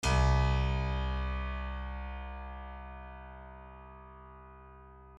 piano-sounds-dev
LoudAndProudPiano